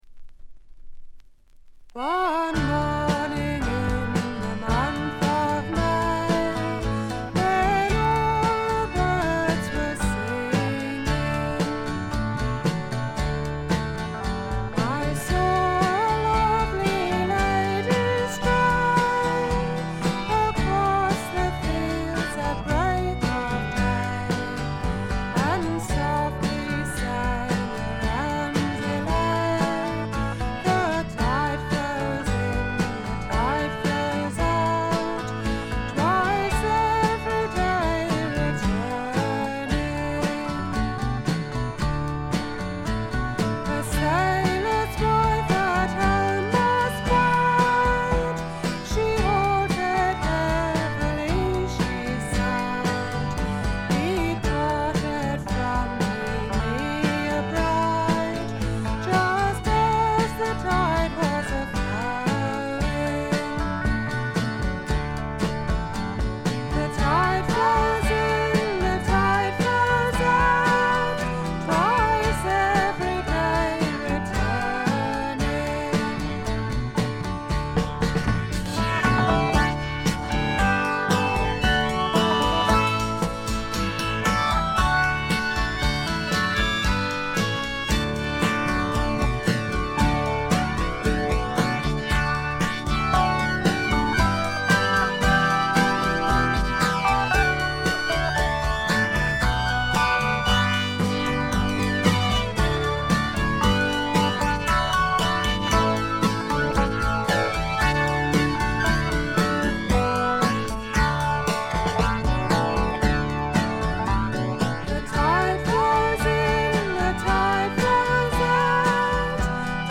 ごくわずかなノイズ感のみ。
エレクトリック・トラッド最高峰の一枚。
試聴曲は現品からの取り込み音源です。